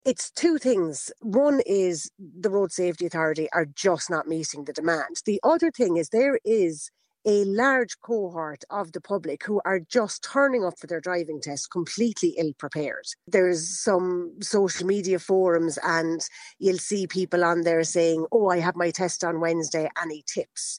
Speaking on Kildare Today